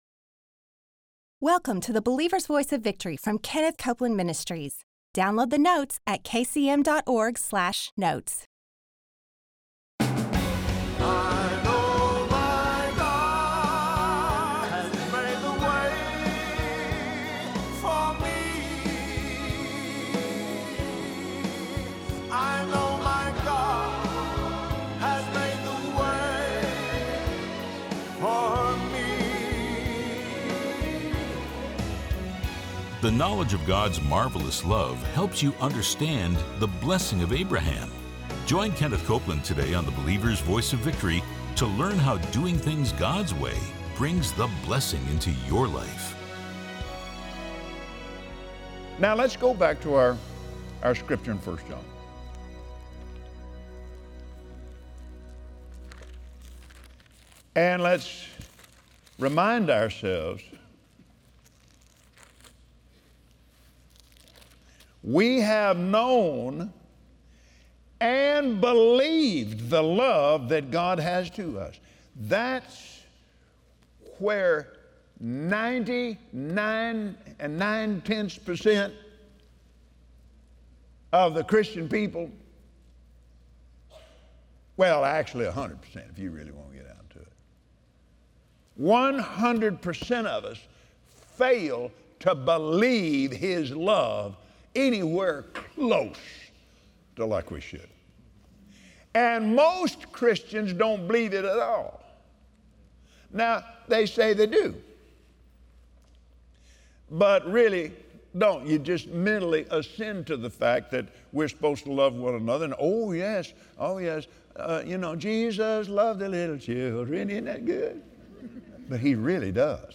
Watch Believer’s Voice of Victory with Kenneth Copeland as he shares how delighting yourself in God’s commandments is the key to living in that BLESSING.